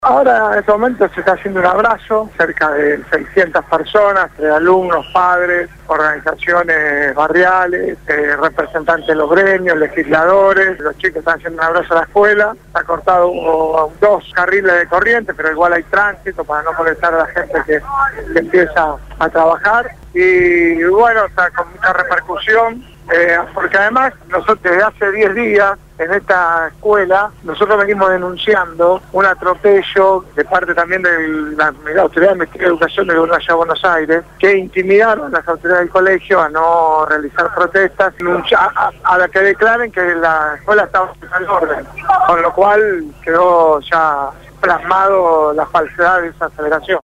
Quién participó del abrazo al Normal 7- habló esta mañana con el programa «Punto de Partida» (Lunes a viernes de 7 a 9 de la mañana) por Radio Gráfica FM 89.3